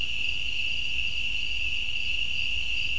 amb_crickets.wav